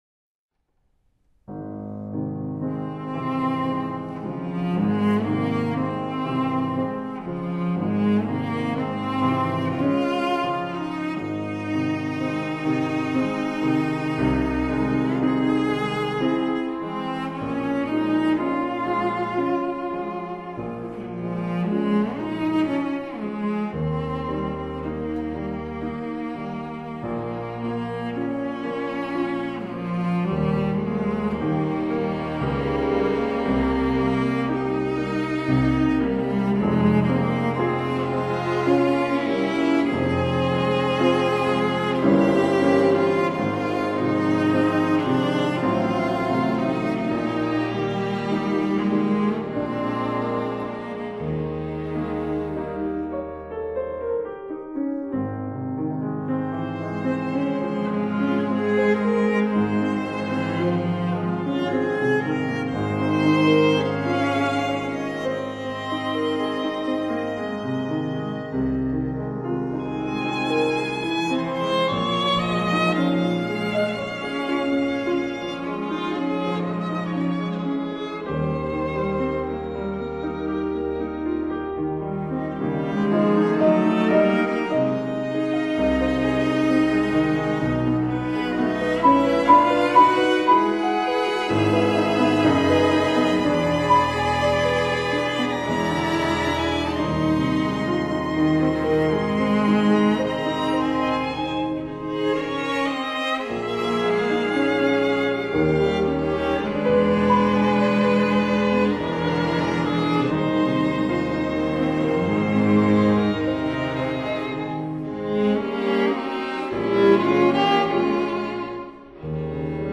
他的鋼琴四重奏充滿熱情、情感豐沛，同時擁有孟德爾頌的浪漫情懷以及舒曼辛辣刺激的斯拉夫元素。